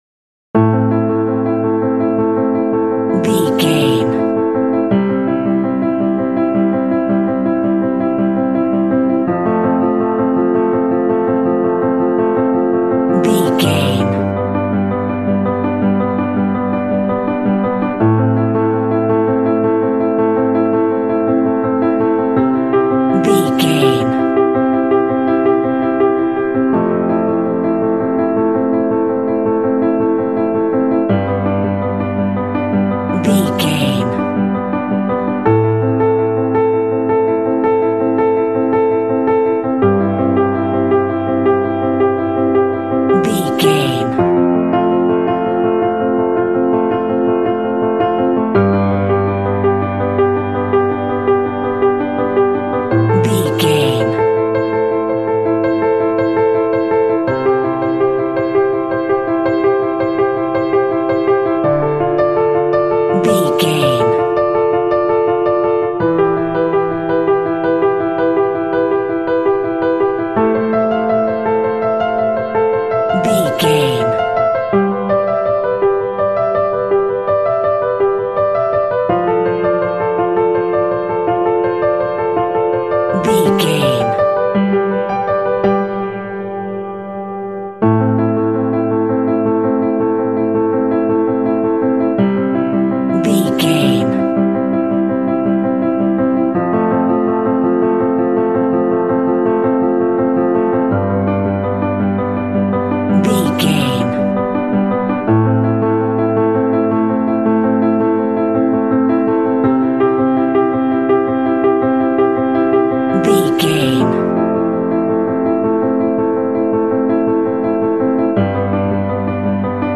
Aeolian/Minor
suspense
foreboding
contemplative
dramatic
cinematic
underscore